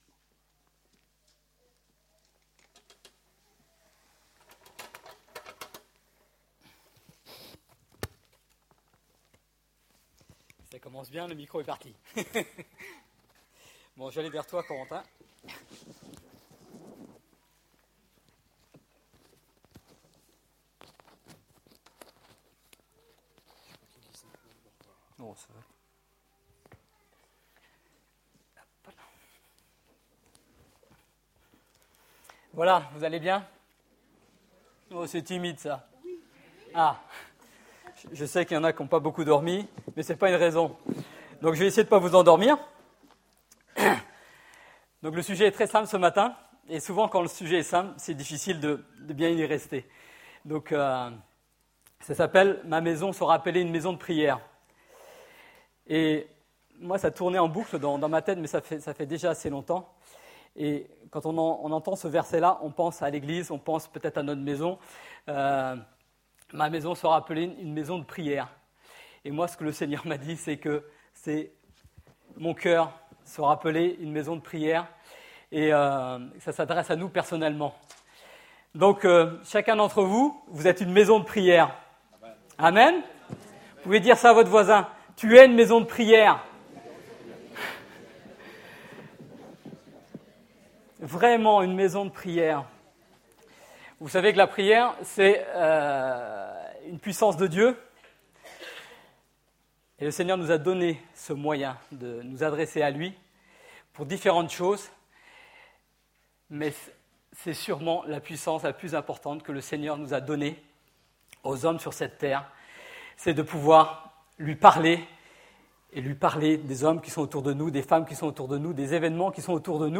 Un message